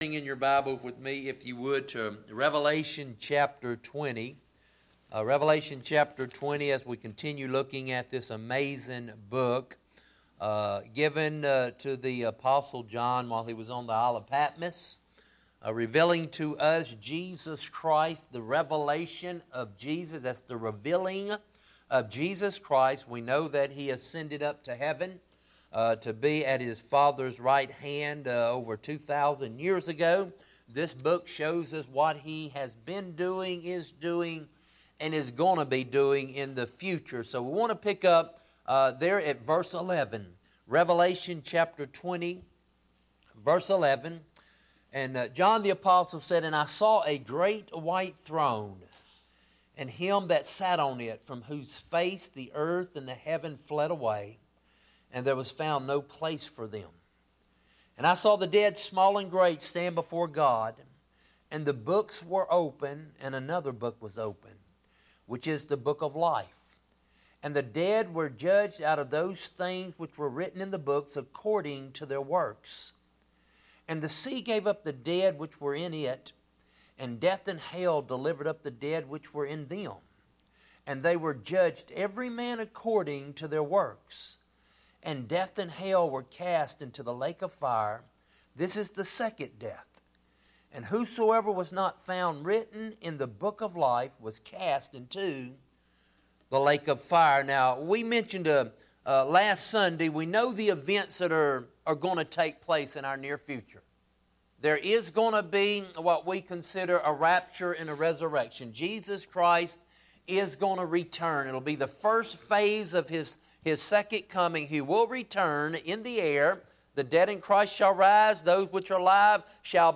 Bible Text: Revelation 20:11-15 | Preacher